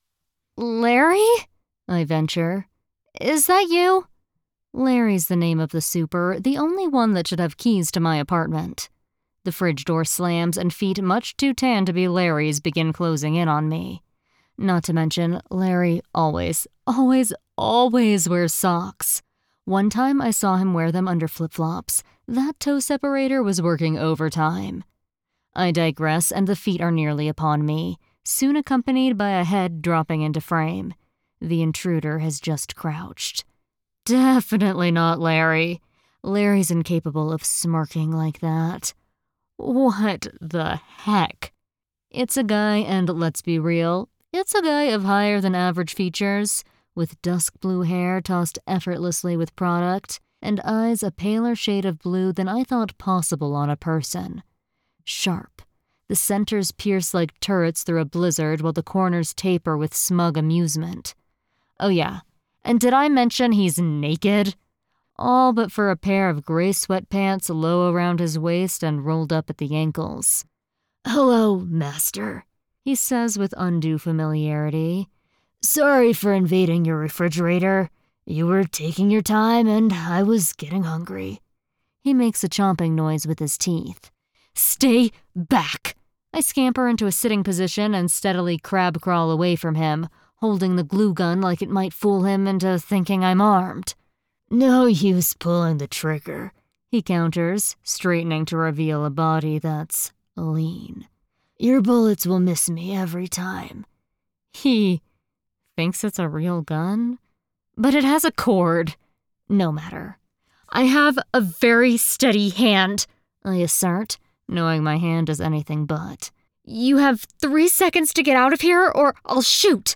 1st Person Romantasy